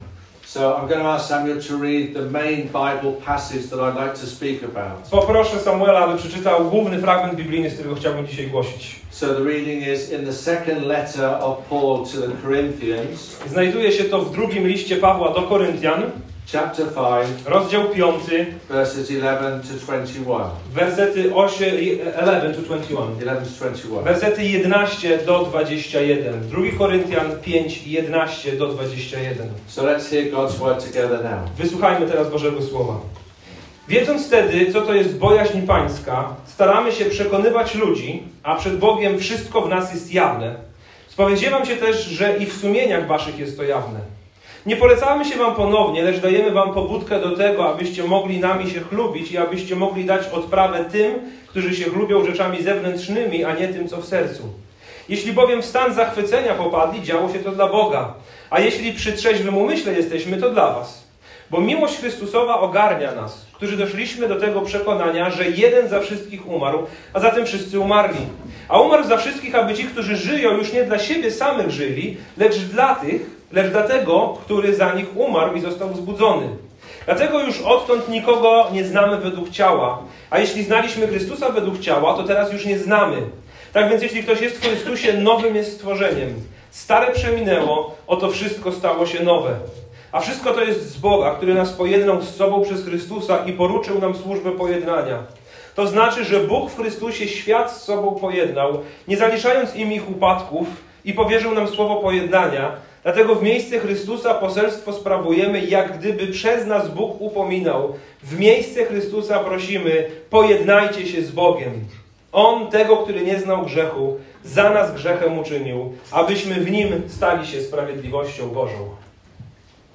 Tego wszystkiego dowiesz się z niniejszego kazania.